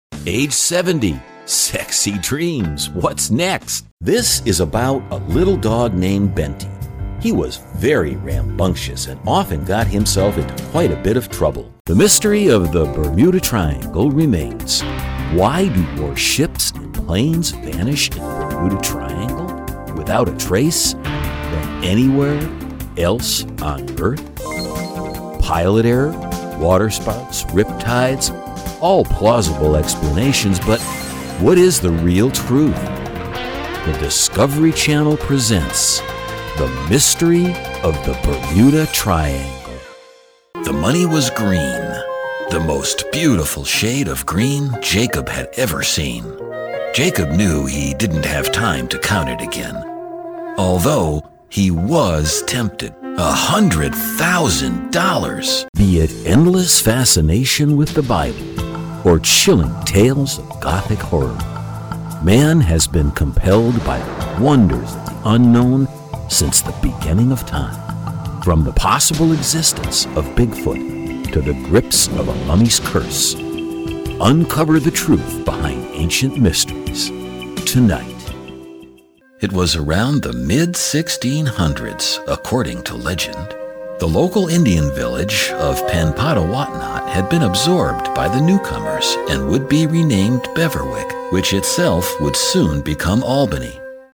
Sprechprobe: Industrie (Muttersprache):
Confident, absolutely believable